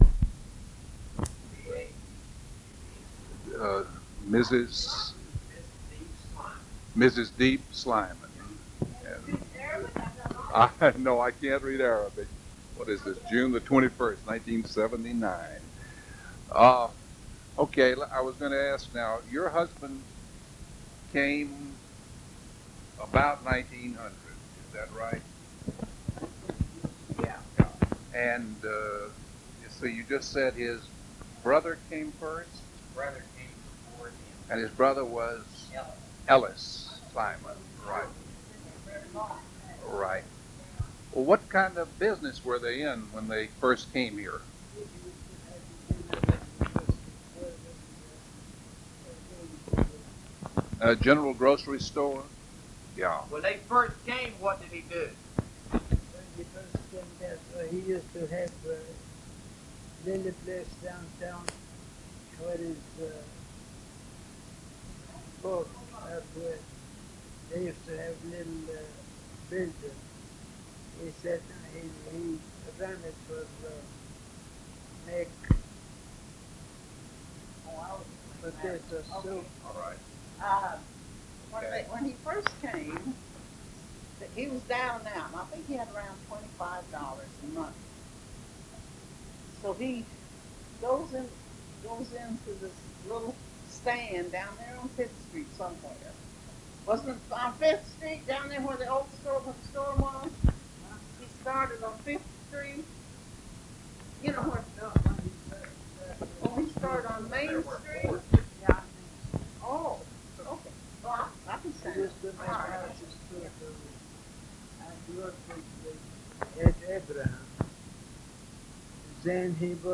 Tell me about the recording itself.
Preface: The following oral history testimony is the result of a cassette tape interview and is part of the Bristow Historical Society, Inc.'s collection of oral histories.